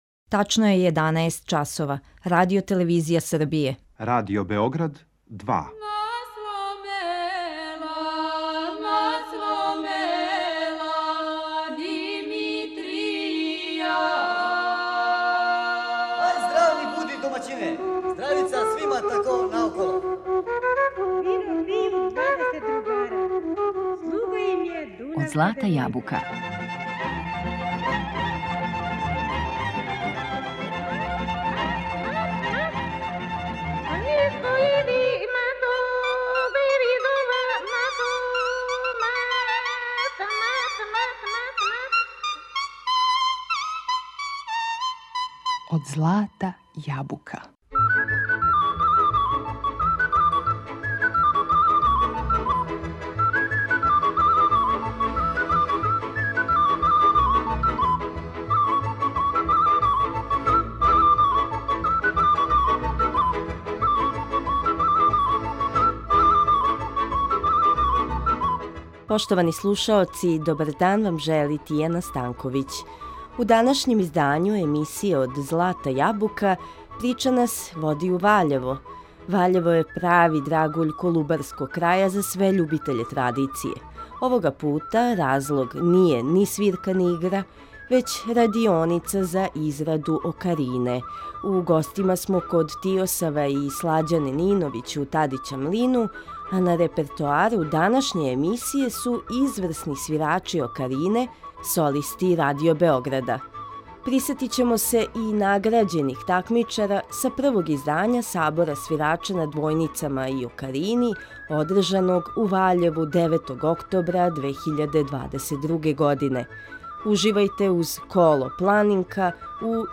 а на репертоару су изврсни свирачи окарине, солисти Радио Београда.